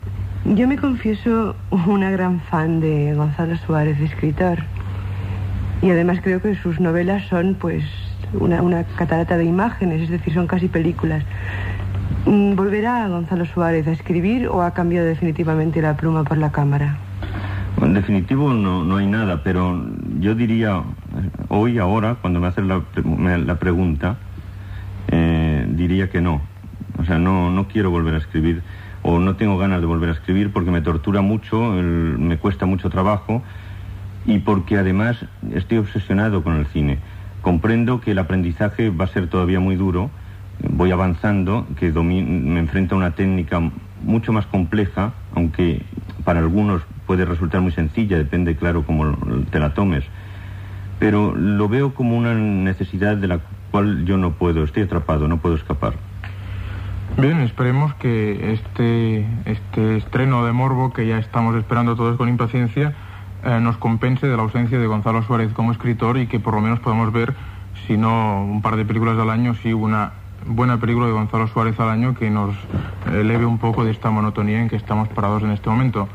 Entrevista al director de cinema Gonzalo Suárez quan estrenava la pel·lícula "Morbo"